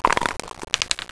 1DICE.WAV